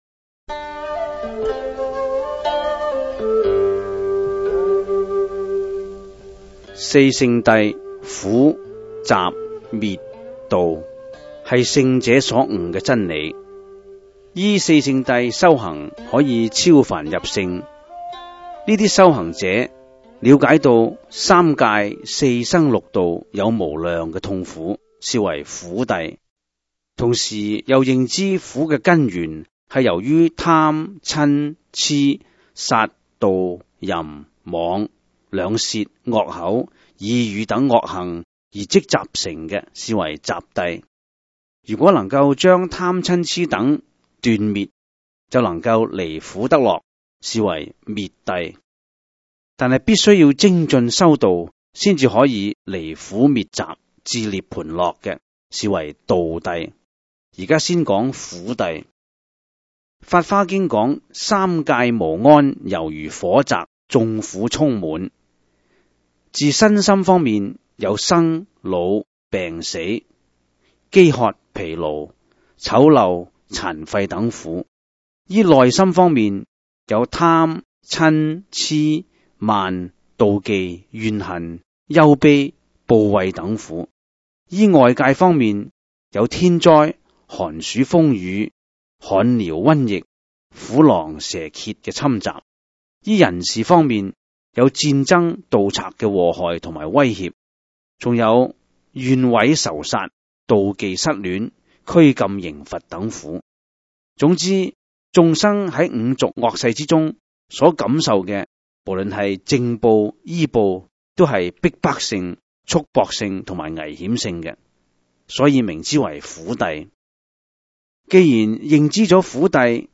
第 二 十 三 辑   (粤语主讲  MP3 格式)